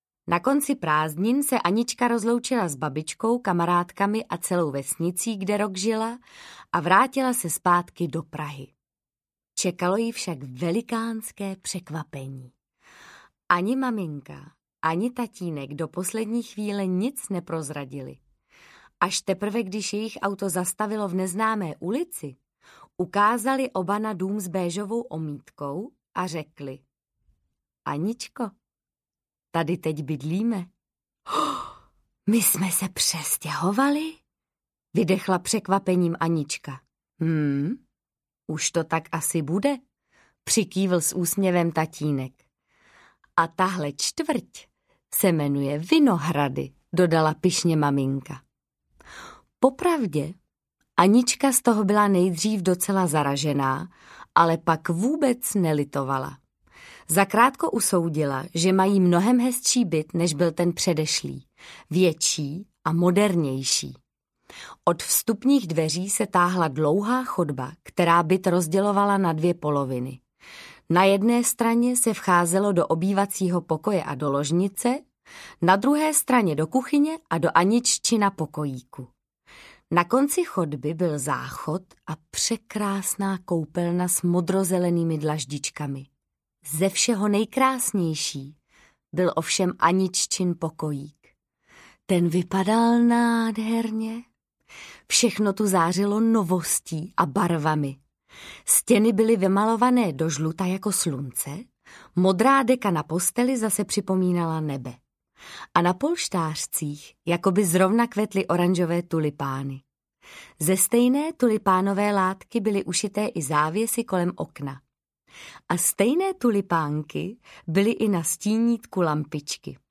Anička ve městě audiokniha
Ukázka z knihy
• InterpretMartha Issová